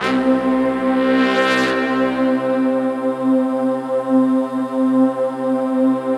Index of /90_sSampleCDs/Optical Media International - Sonic Images Library/SI1_BrassChoir/SI1_SlowChoir
SI1 BRASS03R.wav